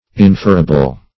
Search Result for " inferable" : The Collaborative International Dictionary of English v.0.48: Inferable \In*fer"a*ble\ ([i^]n*f[~e]r"[.a]*b'l or [i^]n*f[e^]r"-; 277), a. Capable of being inferred or deduced from premises.